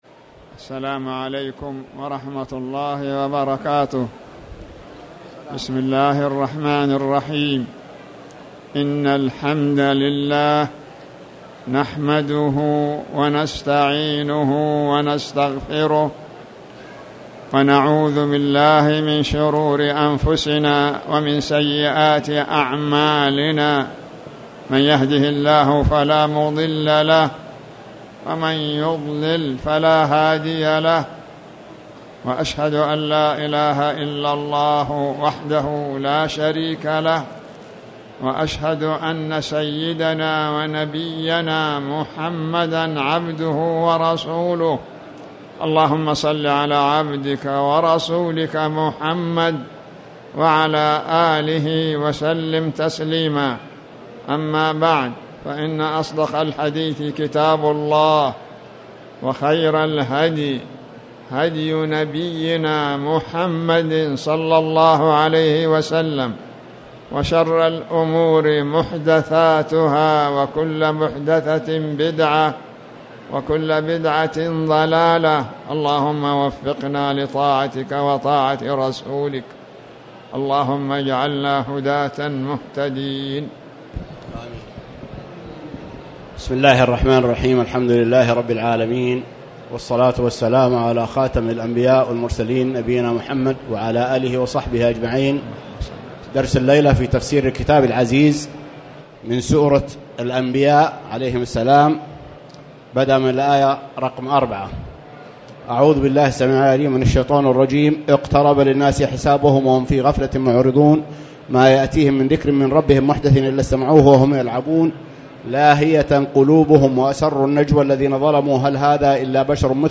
تاريخ النشر ١٦ شوال ١٤٣٨ هـ المكان: المسجد الحرام الشيخ